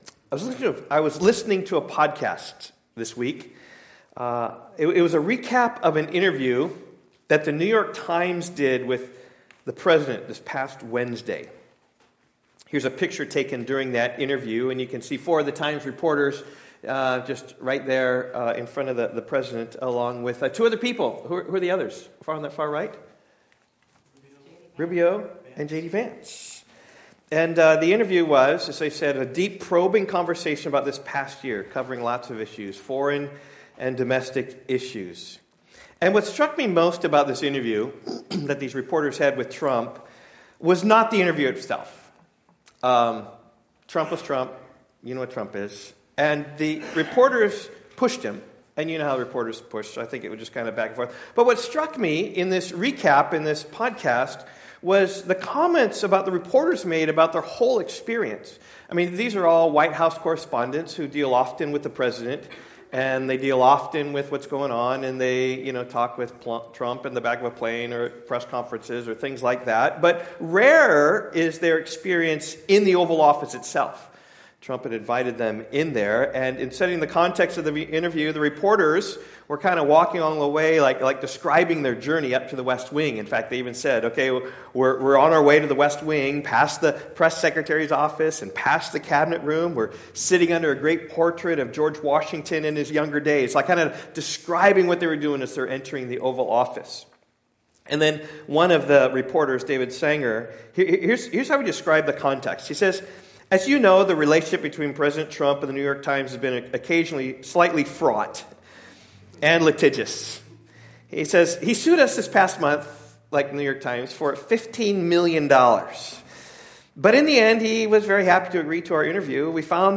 Sermon audio from Rock Valley Bible Church.